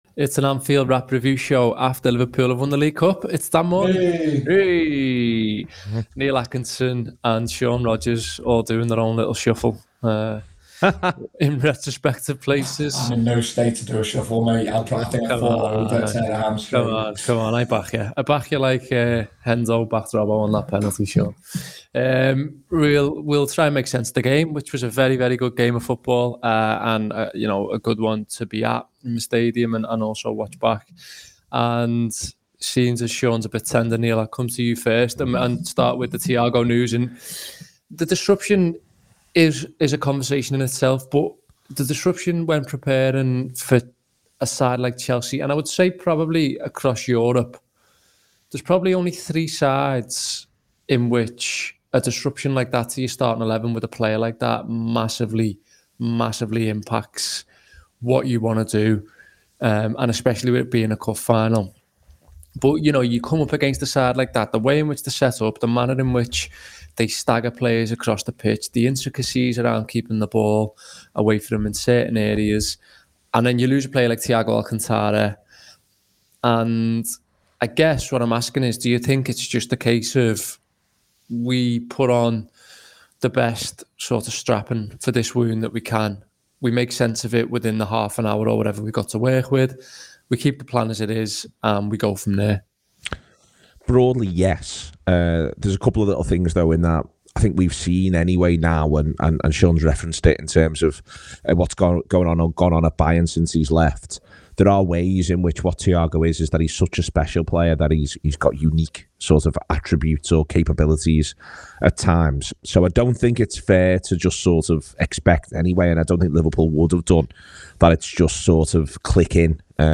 Below is a clip from the show – subscribe for more Liverpool v Chelsea reviews…